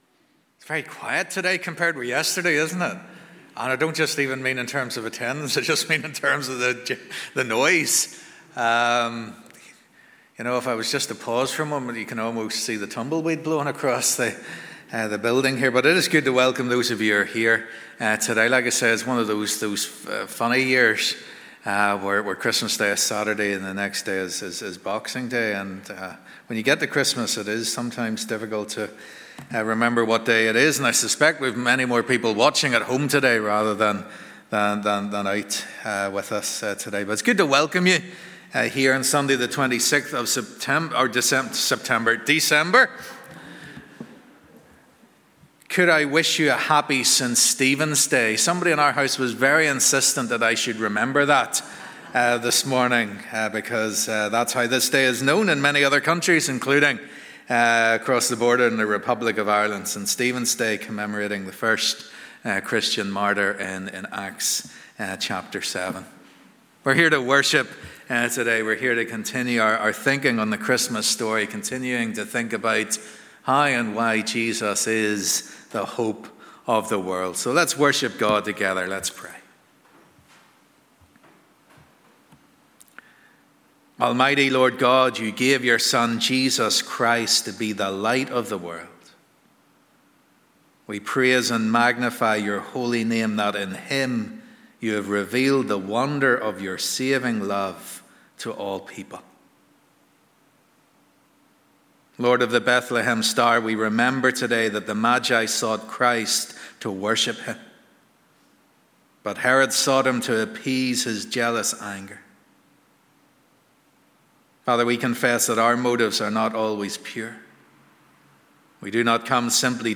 Boxing Day Family Service